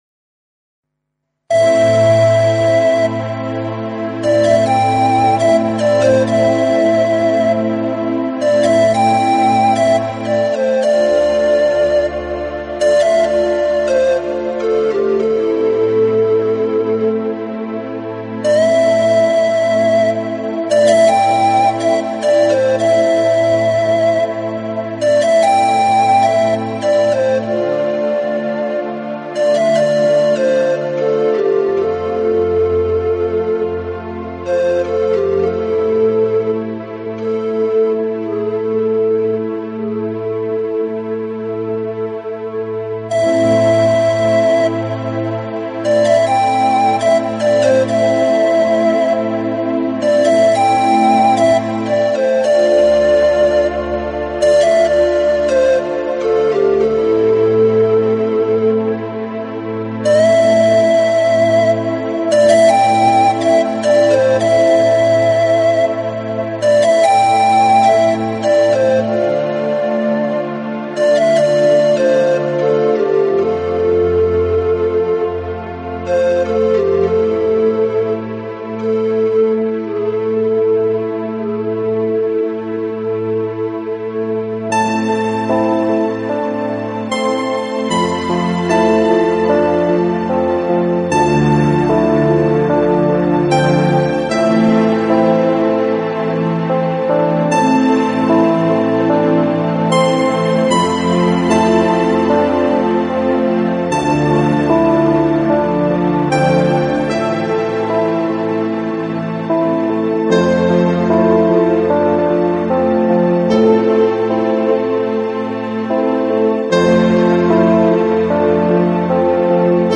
【纯音乐】
音乐类型：New Age
器配置，使每首曲子都呈现出清新的自然气息。